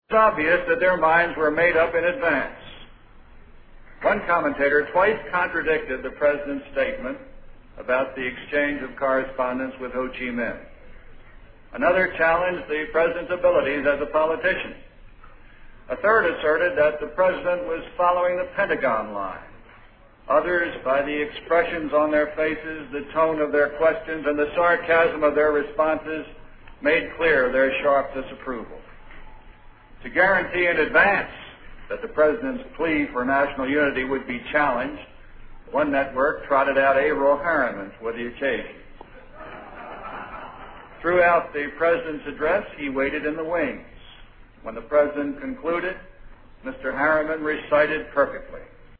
经典名人英语演讲(中英对照):Television News Coverage 2